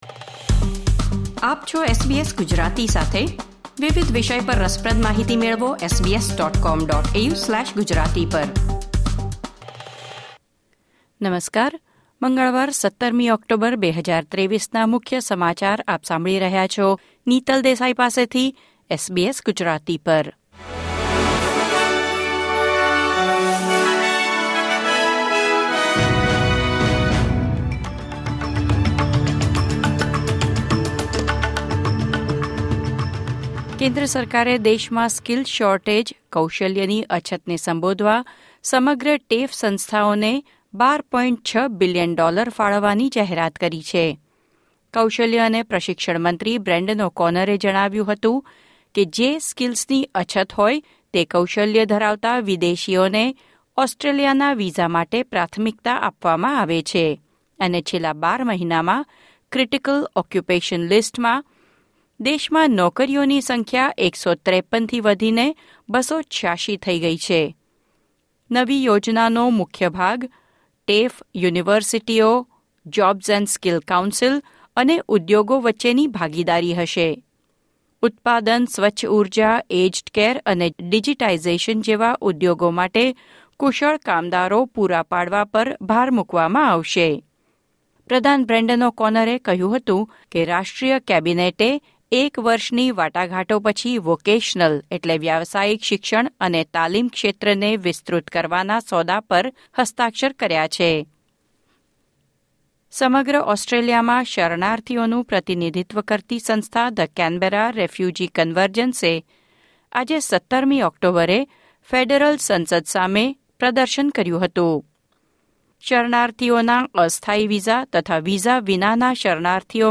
SBS Gujarati News Bulletin 17 October 2023